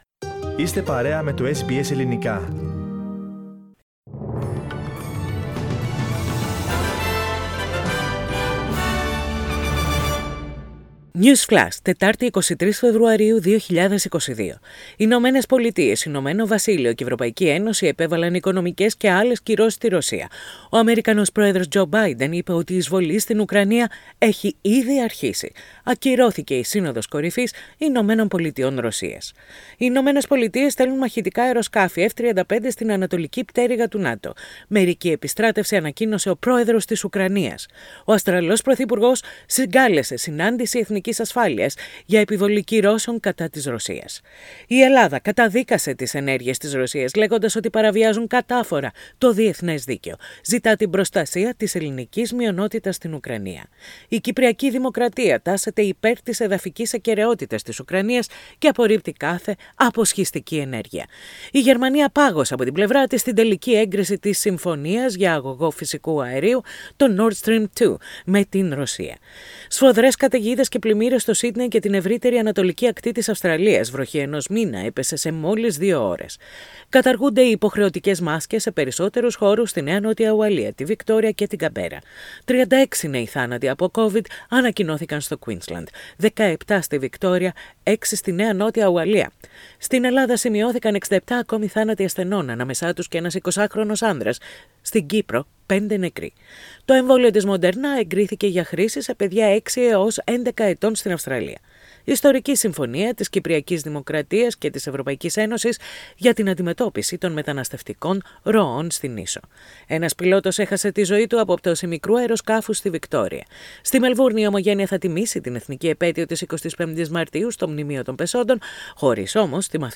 News in headlines. Learn all the news from the world, Australia, Greece and Cyprus in 2 minutes.